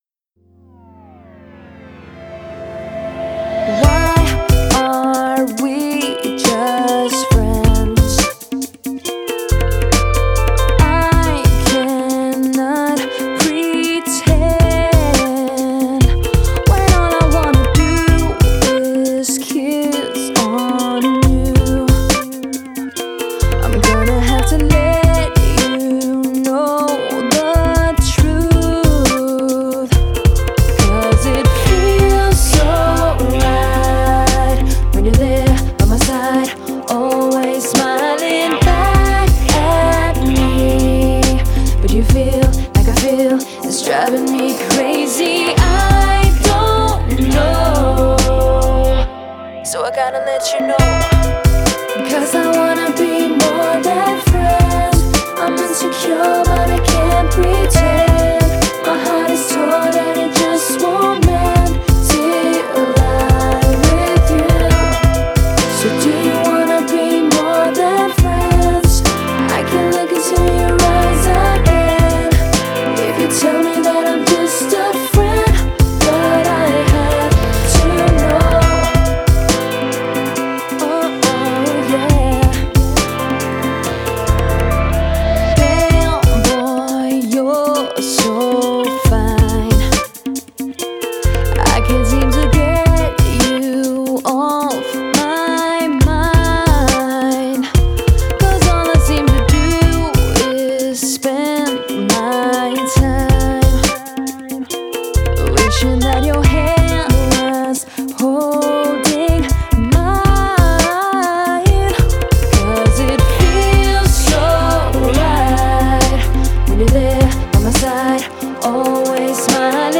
音质：112Kbps 44khz  立体声